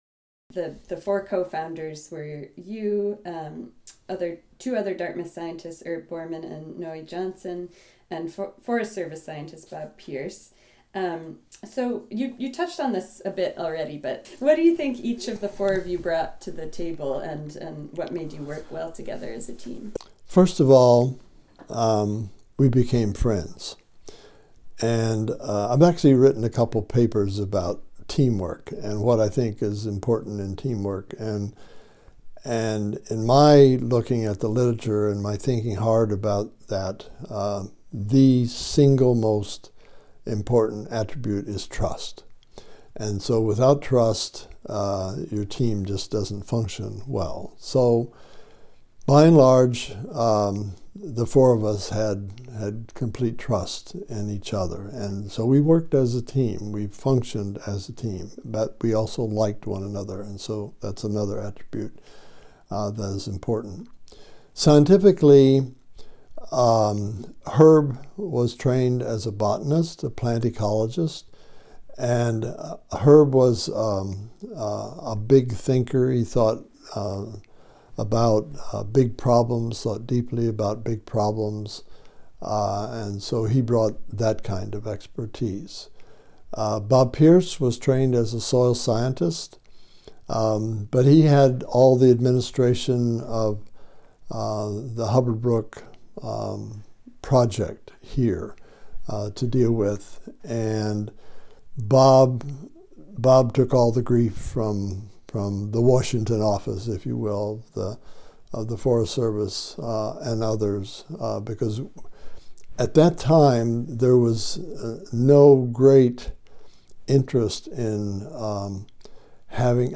An Interview with Gene E. Likens: Pt 3 - Hubbard Brook Ecosystem Study